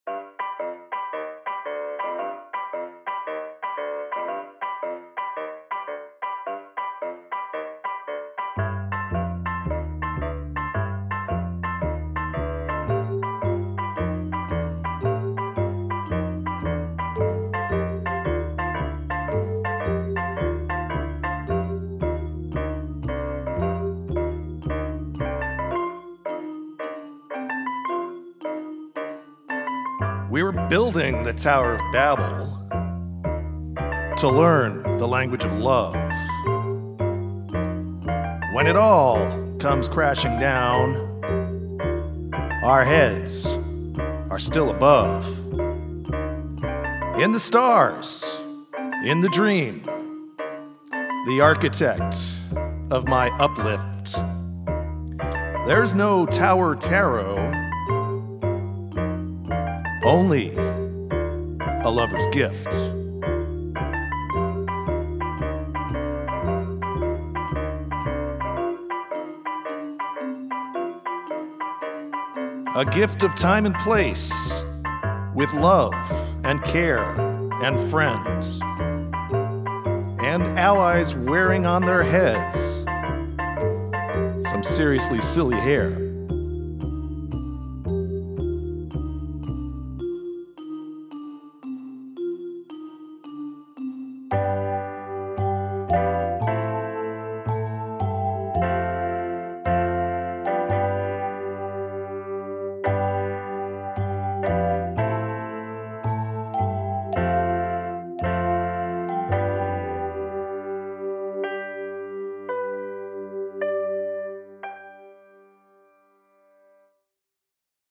Must include a round.